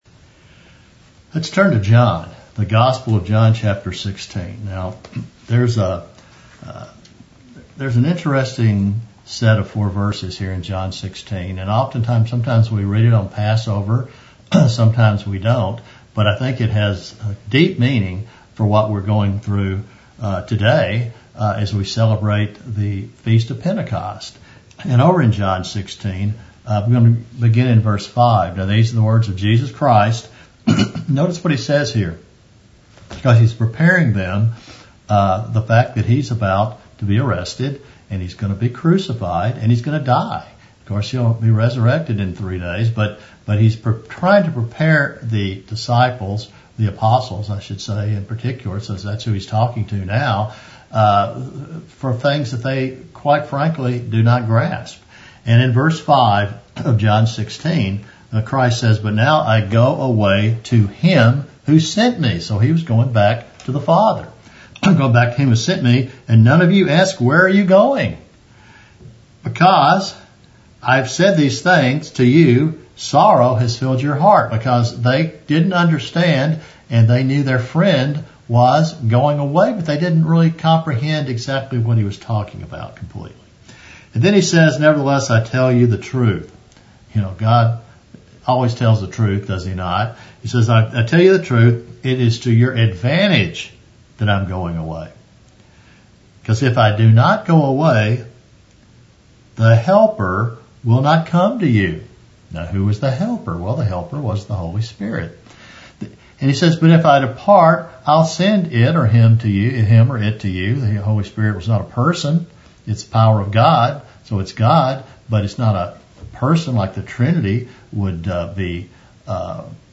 Sermons
Given in Dallas, TX Fort Worth, TX